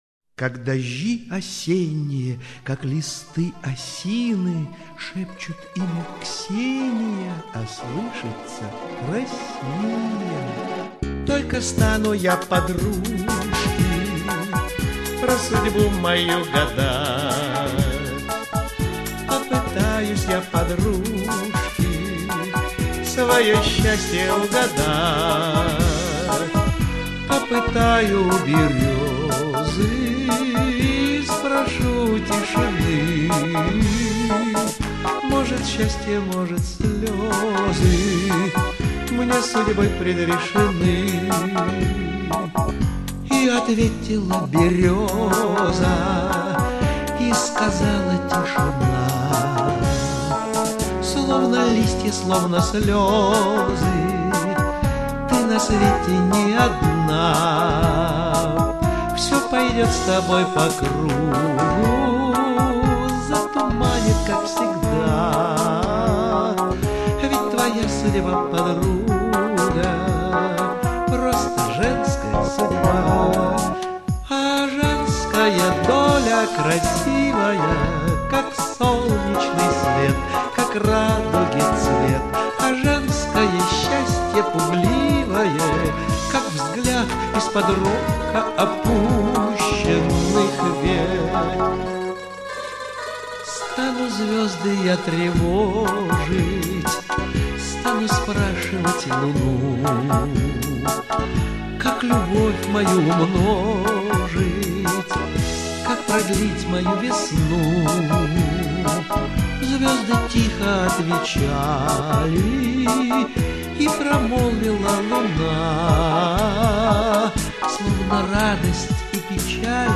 Певцы
Режим: Stereo